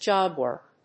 アクセント・音節jób・wòrk